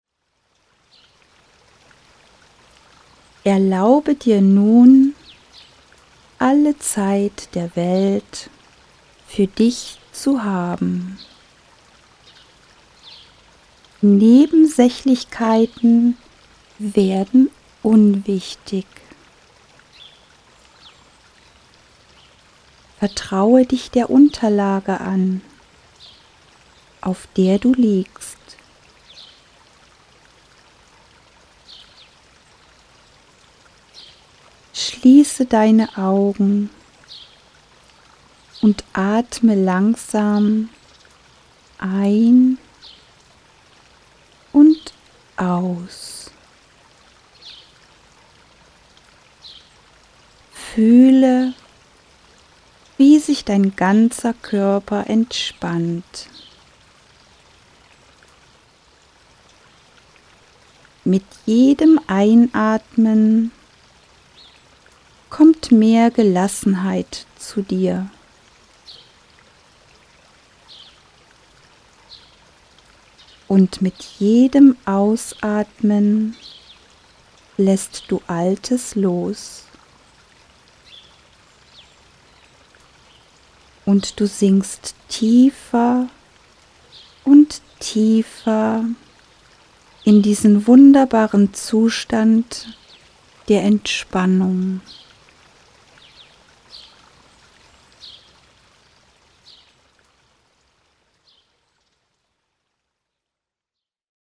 Hier eine Stimmprobe:
Durch meine beruhigenden Worte und gezielte Techniken erreichen Sie die erforderliche hypnotische Trance.